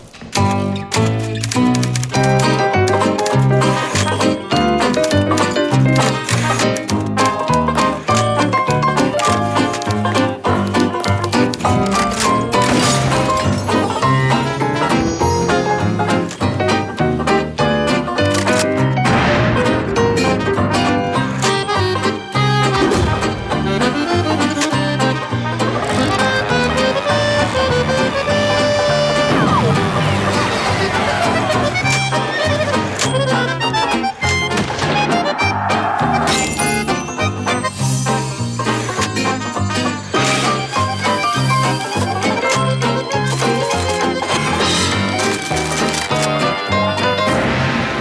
• Качество: 320, Stereo
без слов
инструментальные
русский шансон
выстрелы